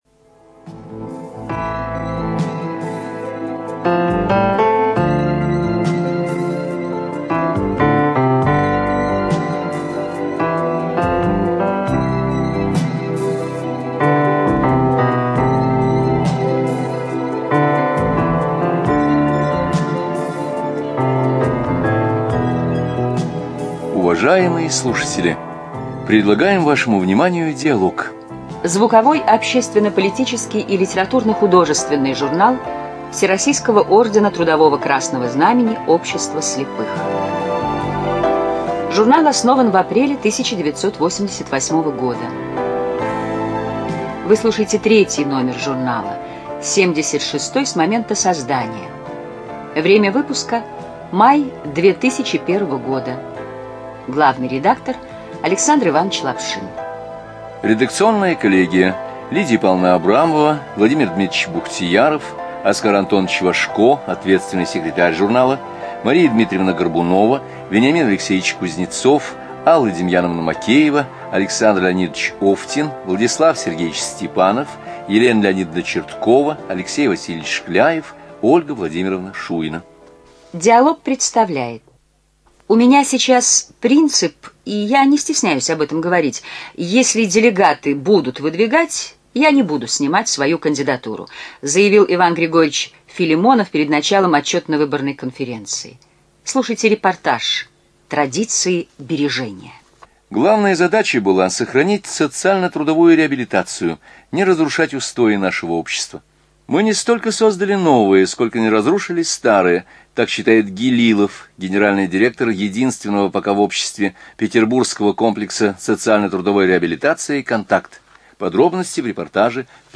ЖанрРеабилитация, Публицистика, Документальные фонограммы
Студия звукозаписиЛогосвос